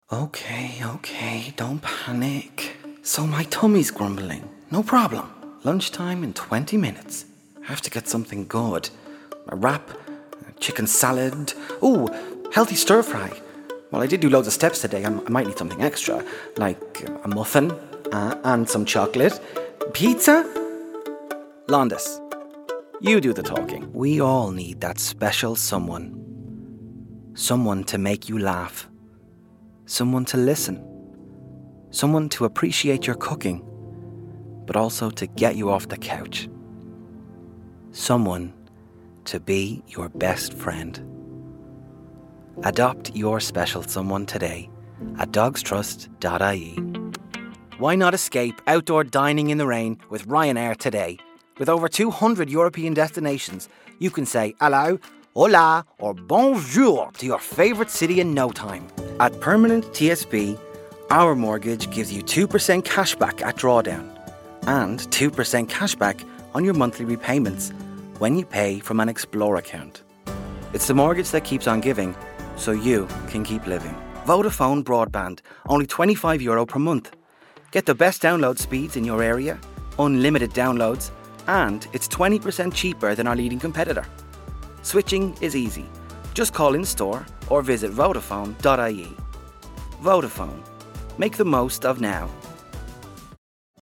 Male
20s/30s, 30s/40s
Irish Neutral